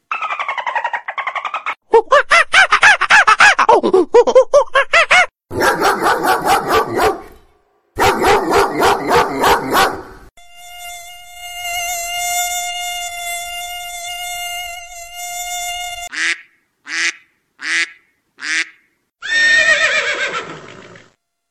Вам нужно пройти маршрут по звукам животных.
дельфин
обезьяна
собака
комар
утка
лошадь